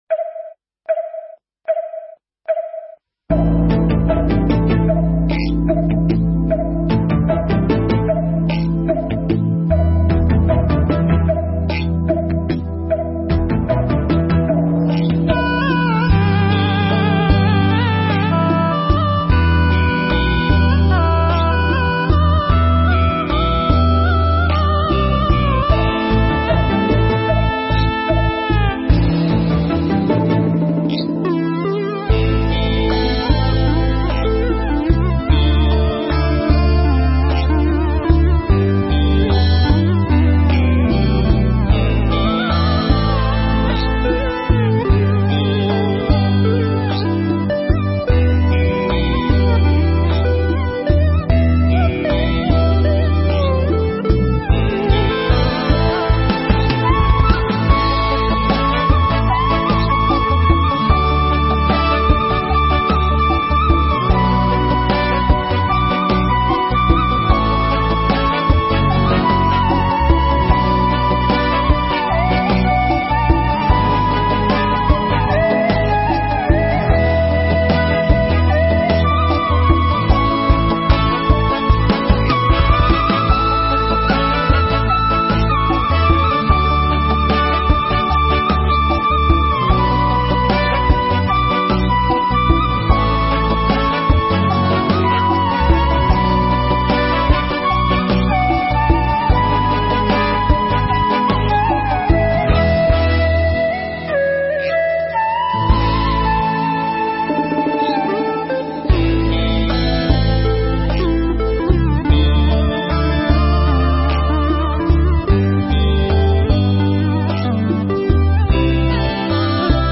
Mp3 Pháp Thoại Thiền Là Gì ?
trong khóa tu Một Ngày An Lạc lần thứ 78 tại Tu Viện Tường Vân